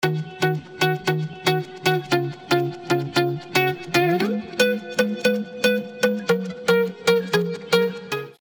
• Качество: 320, Stereo
гитара
без слов
красивая мелодия
инструментальные